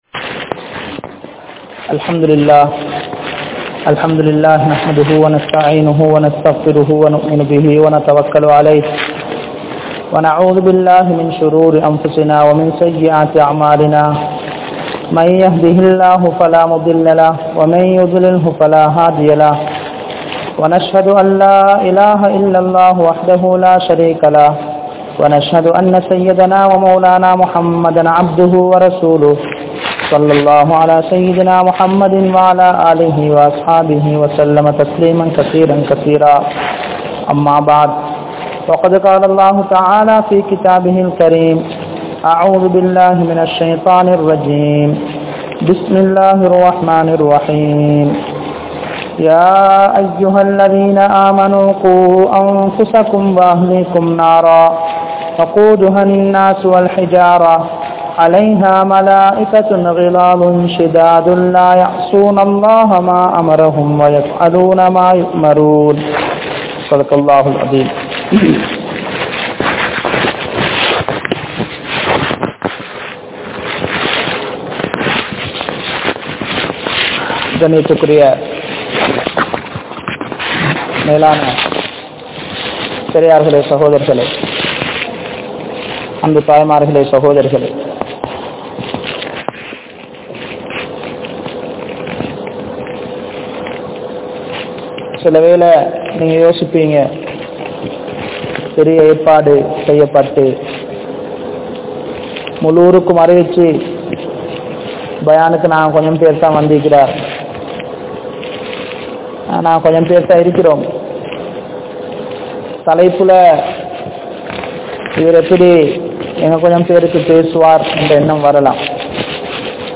Sirantha Kanavanum Manaivium (சிறந்த கனவனும் மனைவியும்) | Audio Bayans | All Ceylon Muslim Youth Community | Addalaichenai
Al-Hiqma Jumua Masjith